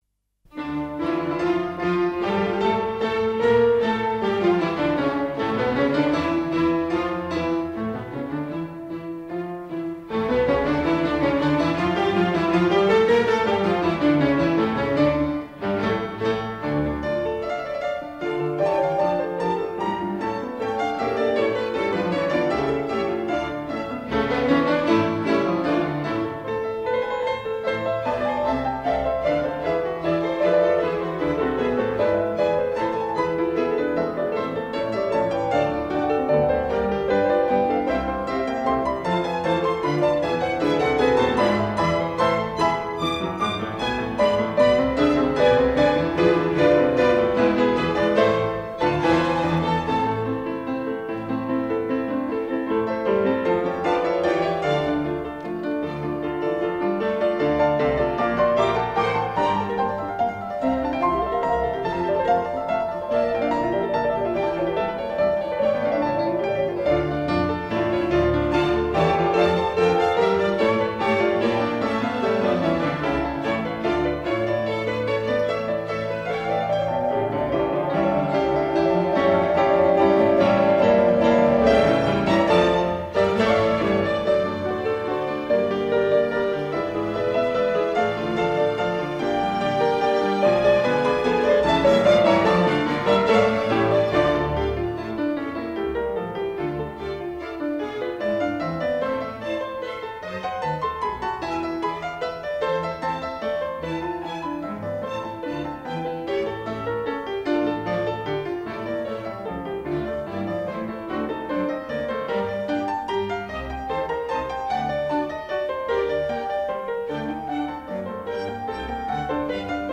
Bach, Johann Sebastian - Concerto for 3 Harpsichords in D minor, BWV 1063 Free Sheet music for Multiple Soloists and Ensemble
Classical
Violin 1 Violin 2 Viola Bass Harpsichord 1 Harpsichord 2 Harpsichord 3